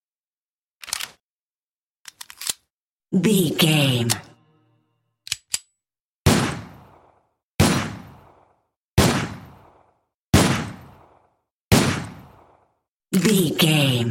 Pistol Equip, Clip eject, Insert, Reload, Firing and Unequip 05 | VGAME
Filled with 10 sounds(44/16 wav.) of Pistol Equip, clip eject, Insert, Reload, Firing(Five single shots) and Unequip.
Sound Effects
Adobe Audition, Zoom h4
Guns Weapons